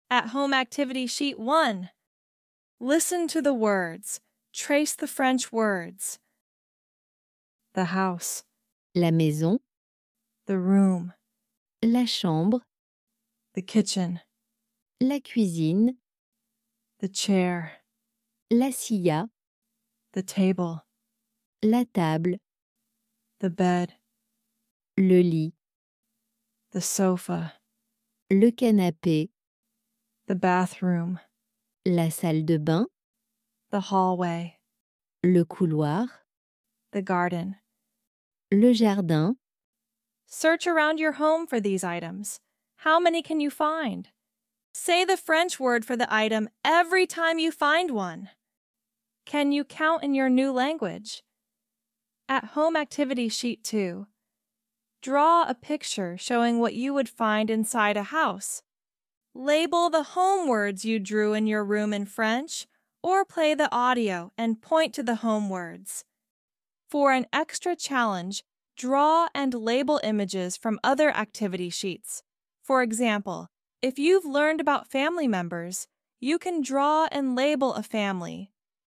Scroll down to download printable worksheets and an audio pronunciation guide, making home vocabulary learning engaging and effortless.